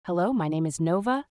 🌐 Multilingual Voices
New multiligual voices available! Those voices can speak in any language depending on your scripts.